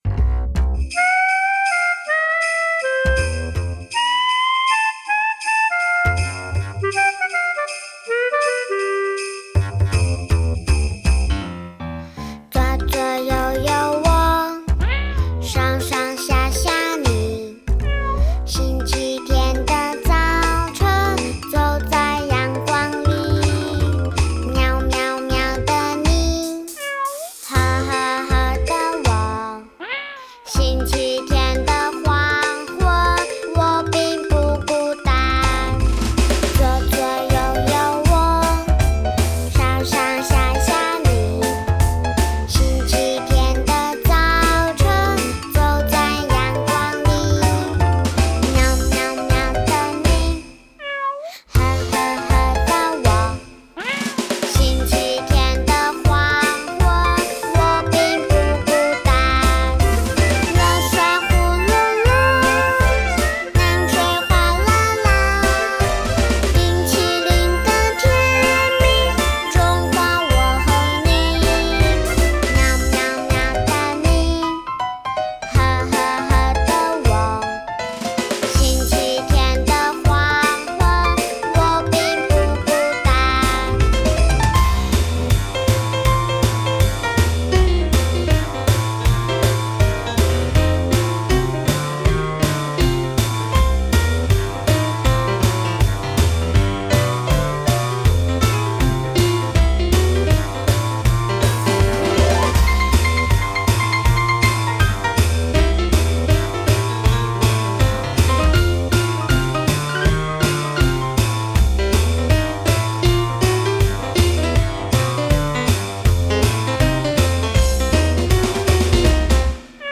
作詞．作曲．演唱 一手包辦
鋼琴．吉他．薩克斯風．爵士鼓